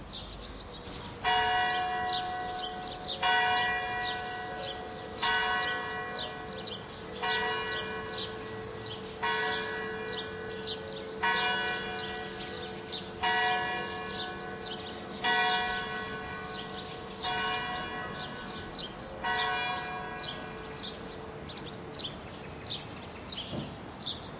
城市的声音 " 1162churchbells
描述：在科隆市的一个星期六中午，教堂的钟声响起。Sony ECMMS907, Marantz PMD671.